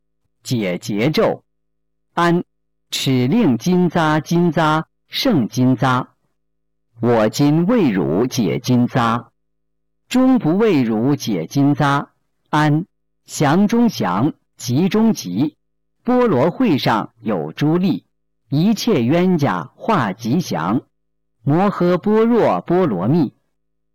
009《解结咒》教念男声